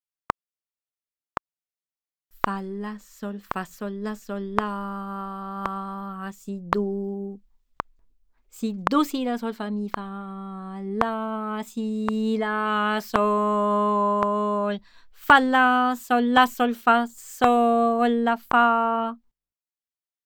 Rythme 01